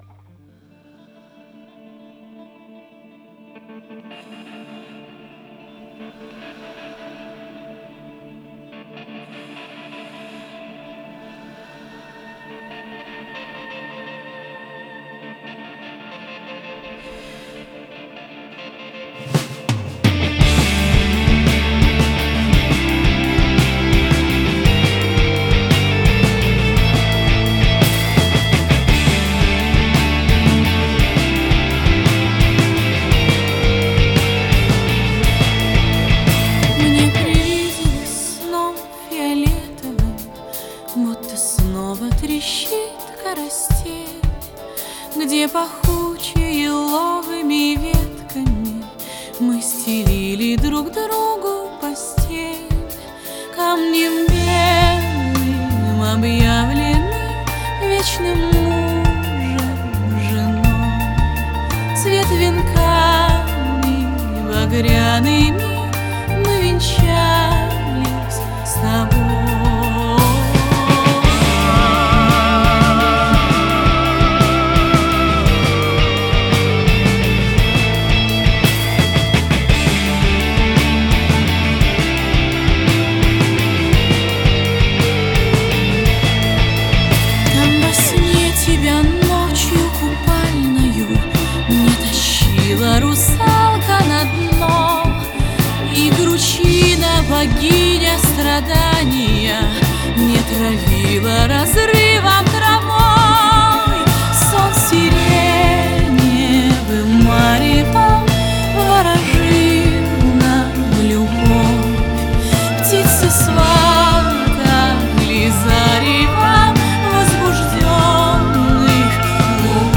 Этническая музыка